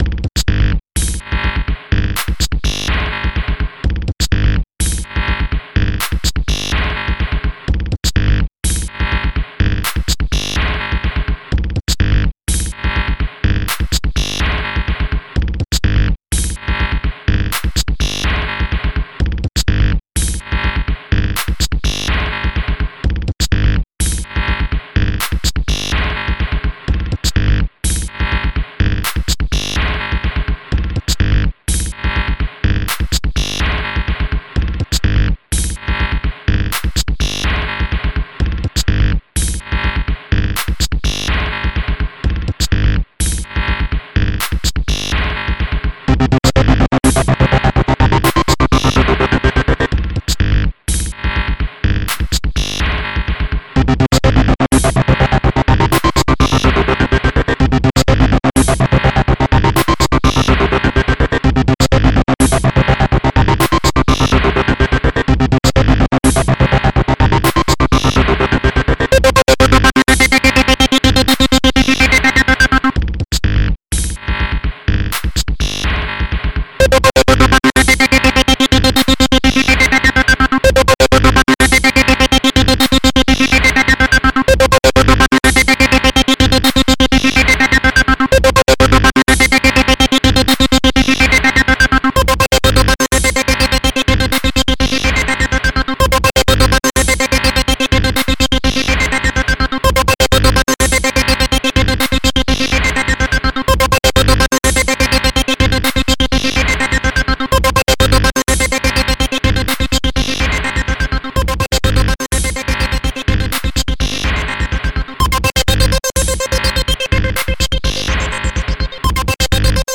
..drum n bass style was..